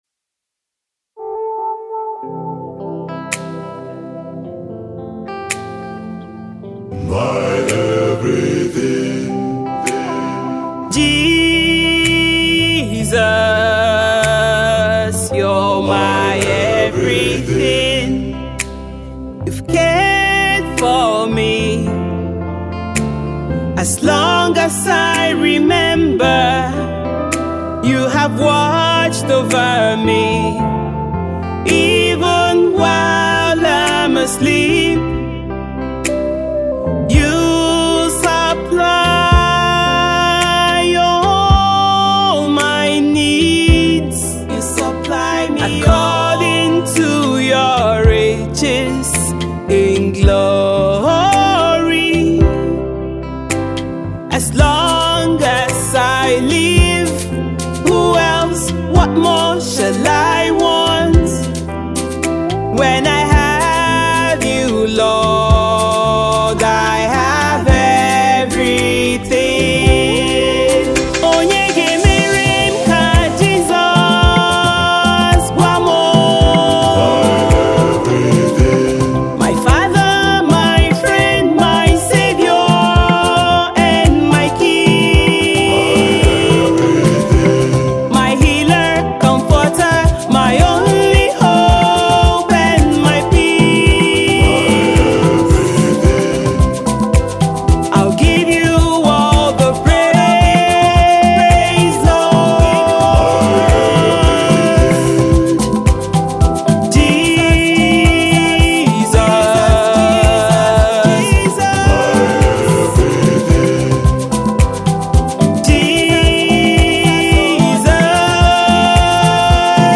The Afrocentric track is delivered in an Igbo-English blend.